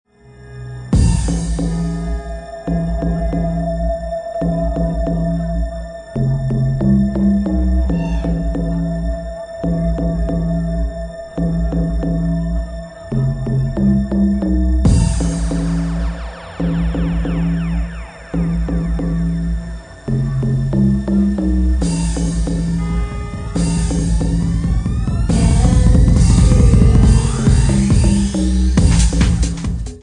at 138 bpm